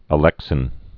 (ə-lĕksĭn)